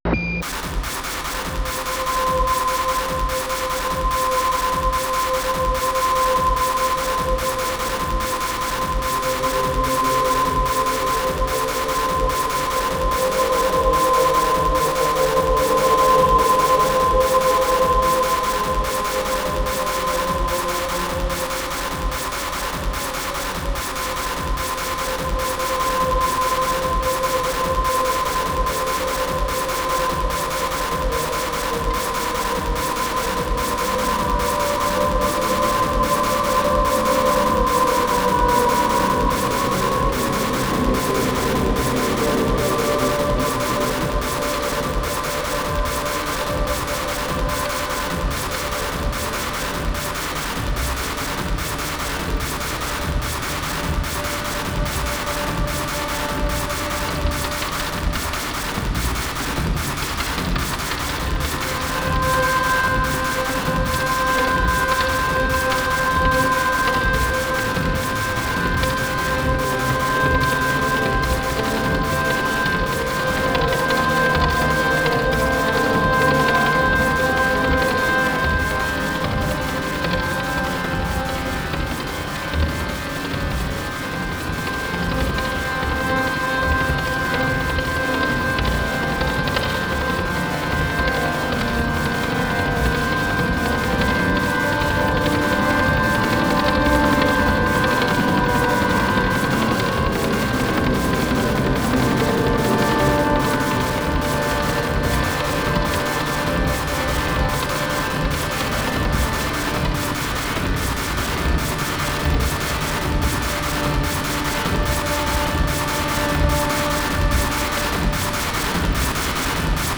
合唱、
音声モーフィング、
オルガン、
ハードコア・ビート、
不整動パンニング、
非実存ギターによるパーカッシブ・タッピング、